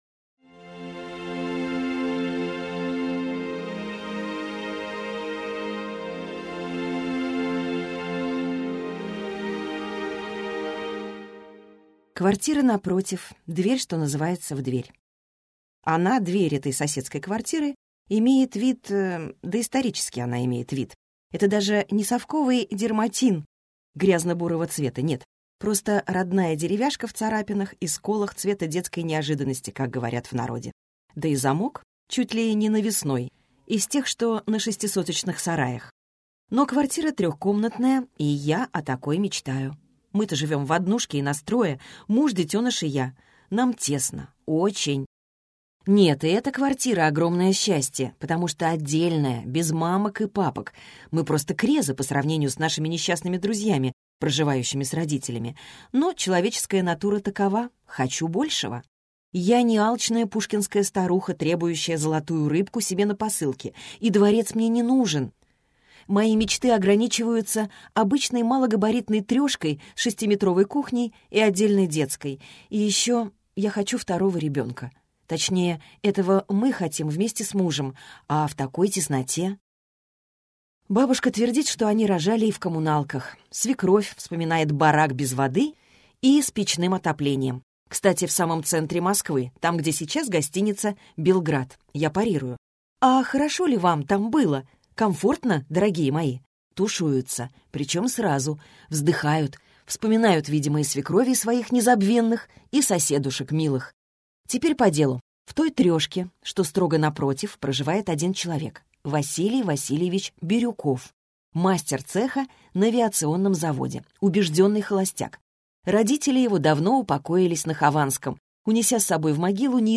Аудиокнига Такова жизнь (сборник) | Библиотека аудиокниг